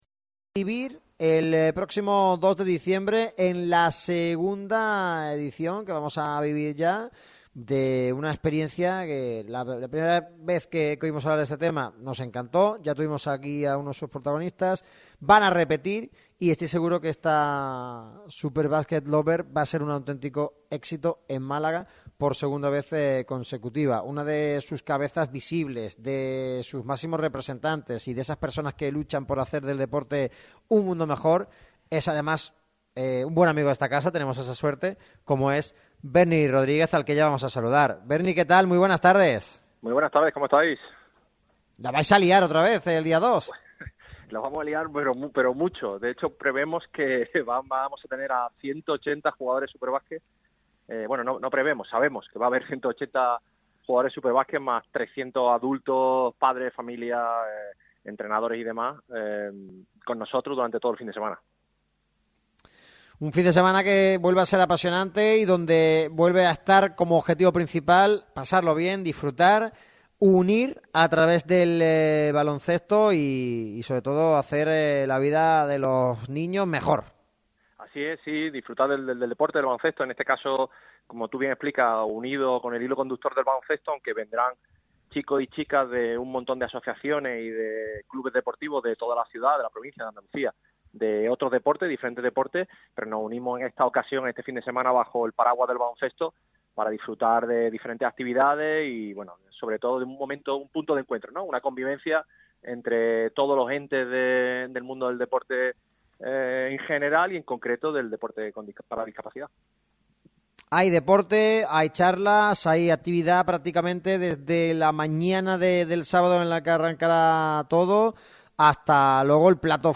Berni Rodríguez explicó para Radio Marca Málaga el funcionamiento y en qué consiste el evento de SuperBasket Lover, que tendrá lugar este fin de semana en Málaga.